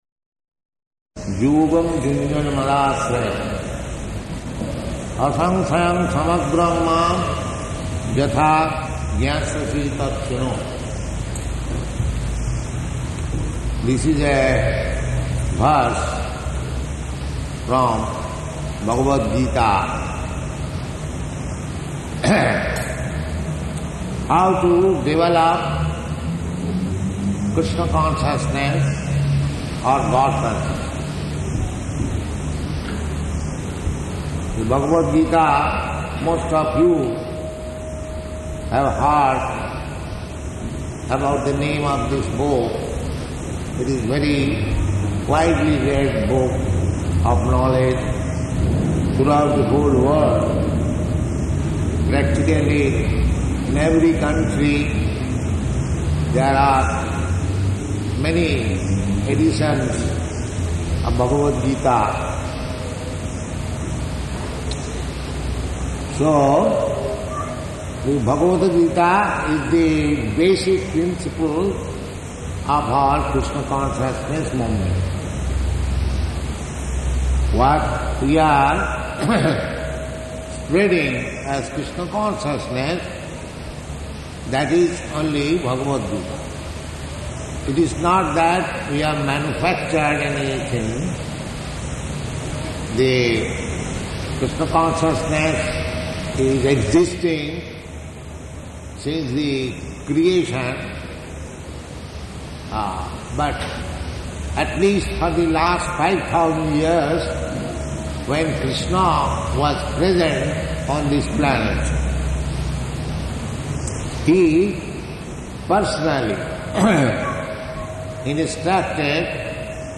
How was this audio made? Location: Sydney